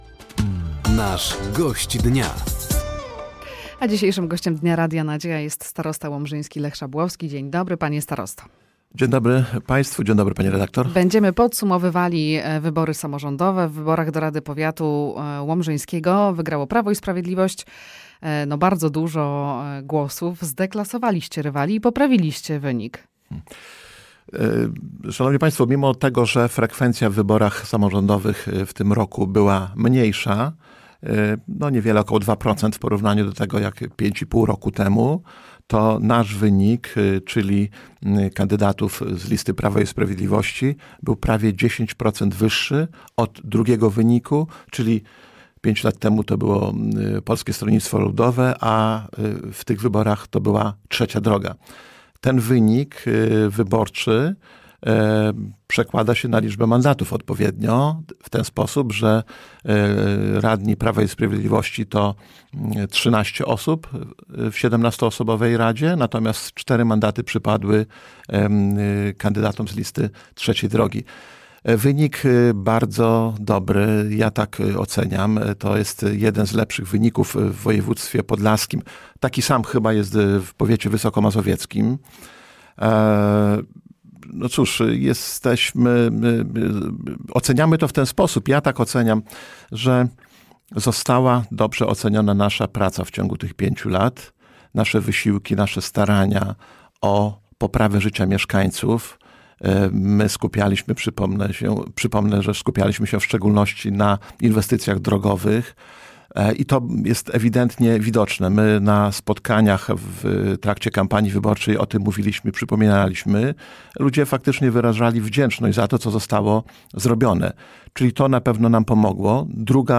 Gościem Dnia Radia Nadzieja był starosta powiatu łomżyńskiego Lech Szabłowski. Tematem rozmowy był wynik wyborów samorządowych.